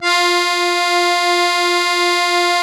MUSETTE 1 .7.wav